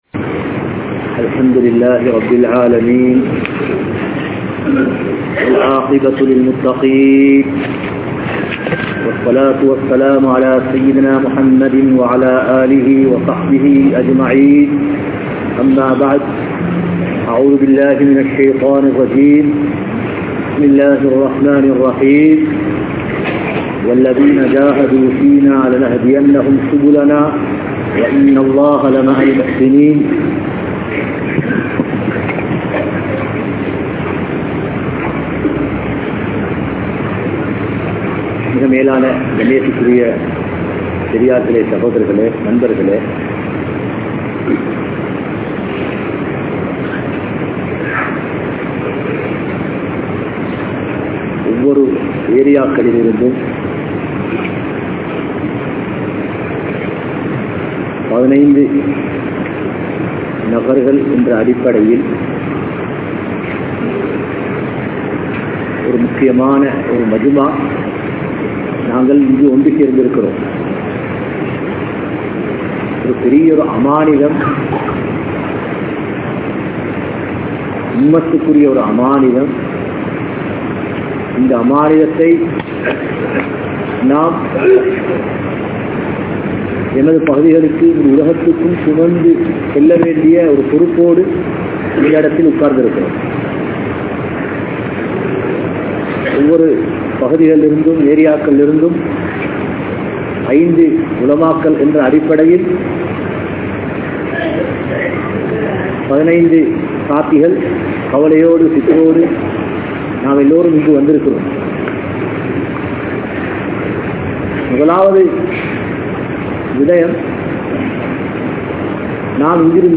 Ummaththin Poruppu (உம்மத்தின் பொறுப்பு) | Audio Bayans | All Ceylon Muslim Youth Community | Addalaichenai
Colombo, GrandPass Markaz